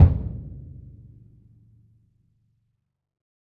BDrumNewhit_v5_rr2_Sum.wav